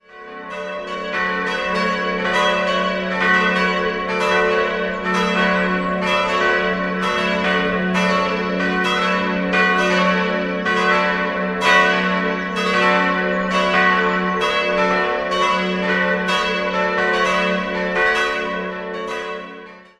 Im Inneren findet der Besucher eine reichhaltige Ausstattung vor. 4-stimmiges Geläute: fis'-a'-h'-cis'' Die beiden großen Glocken wurden 1953, die kleine 1956 von Karl Czudnochowsky in Erding gegossen. Die zweitkleinste stammt noch aus dem Jahr 1933 und entstand bei Wolfart in Lauingen.